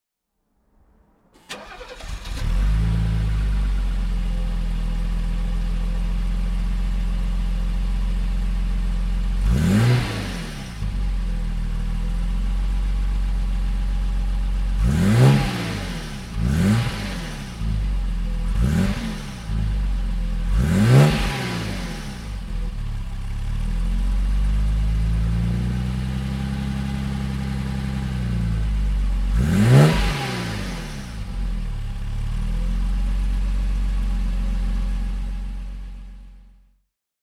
Mercedes-Benz 280 E (1975) - Starten und Leerlauf